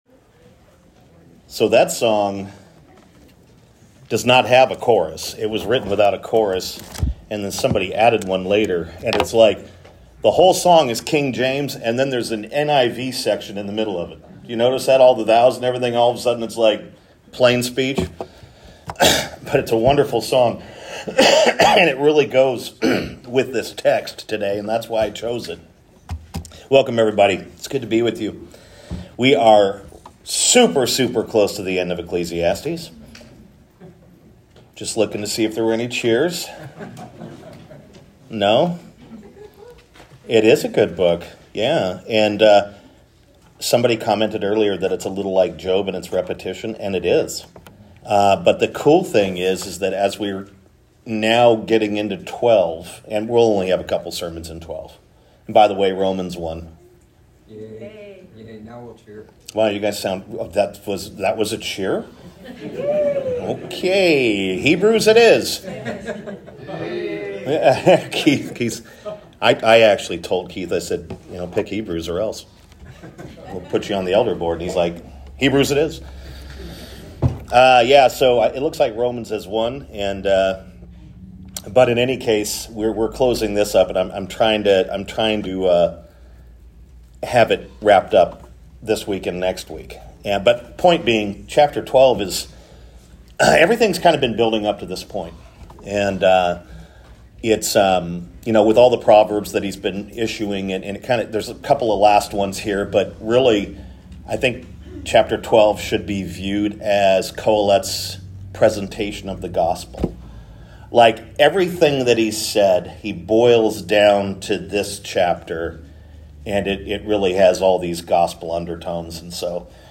Sermons
sermon-april-6.m4a